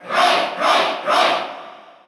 Category: Crowd cheers (SSBU) You cannot overwrite this file.
Roy_Koopa_Cheer_Russian_SSBU.ogg